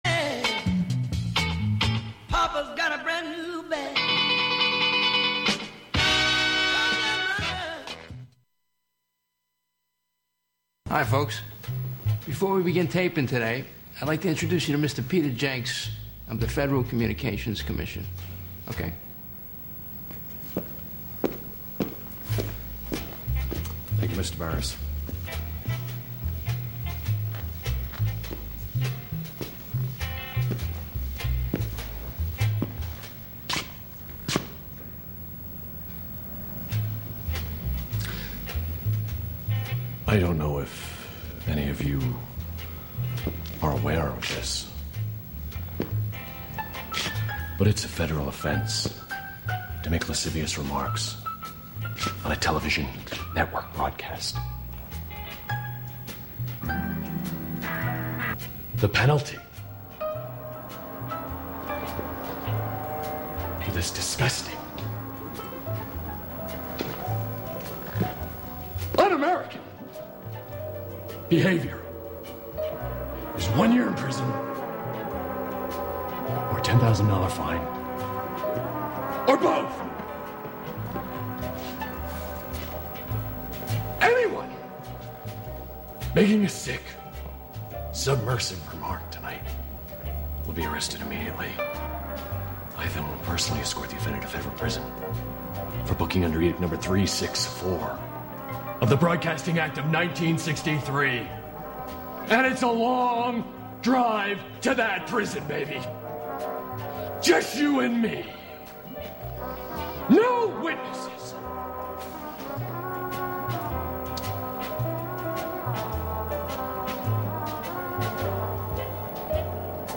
On this monthly program, “La Ville Inhumaine” (The Inhuman City), you will hear music, found sounds, words, intentional noise, and field recordings all together, all at once.